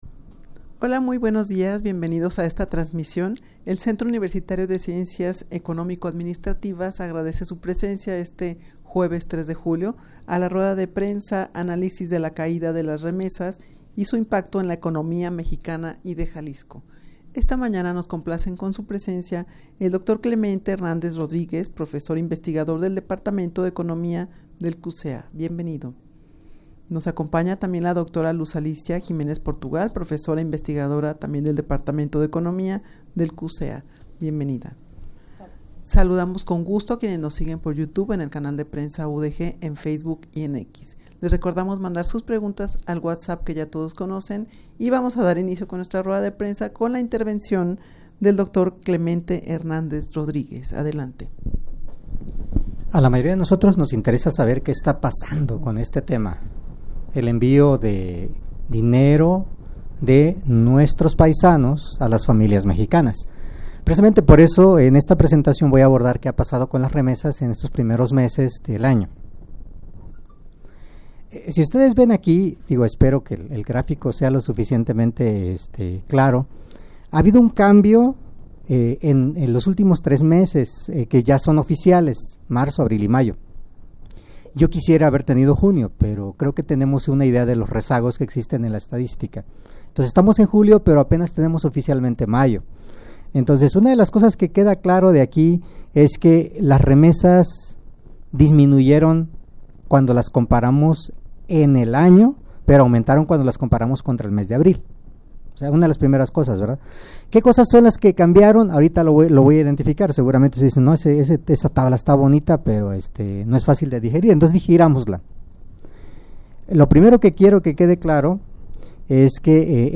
rueda-de-prensa-analisis-de-la-caida-de-las-remesas-y-su-impacto-en-la-economia-mexicana-y-de-jalisco_0.mp3